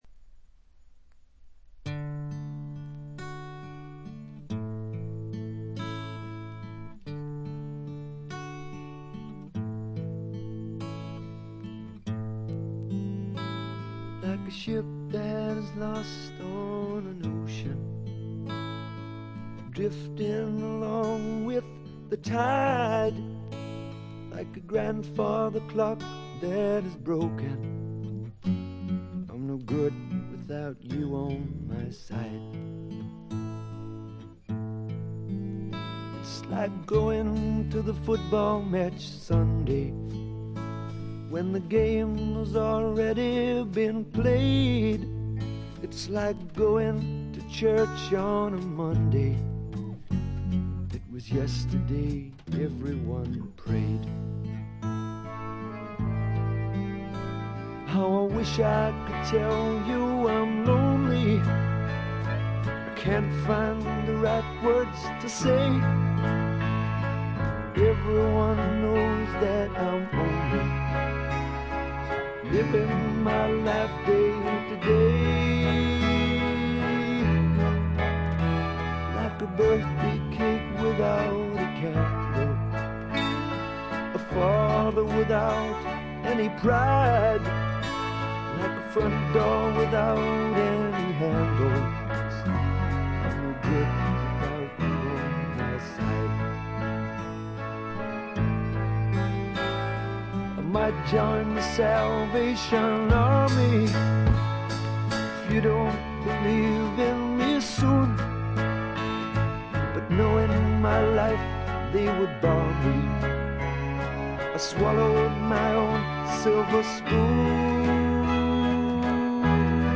静音部で微細なチリプチが少し認められる程度でほとんどノイズ感無し。
ちょいと鼻にかかった味わい深いヴォーカルがまた最高です。
試聴曲は現品からの取り込み音源です。